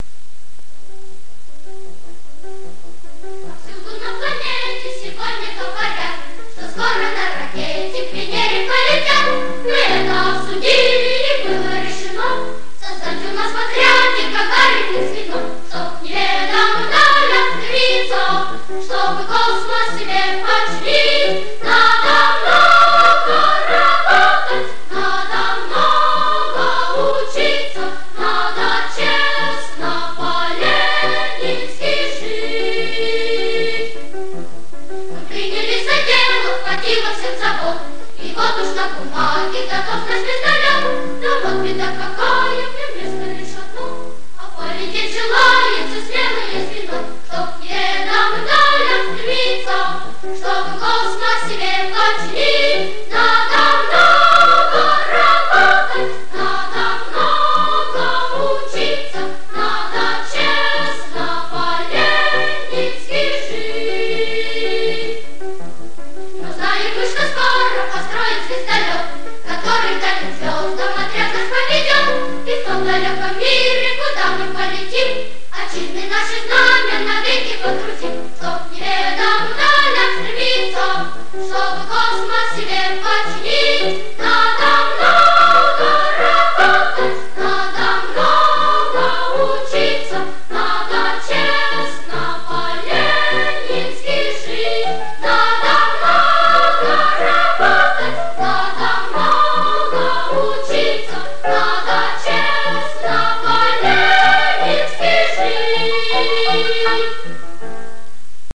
Детский хор, 1969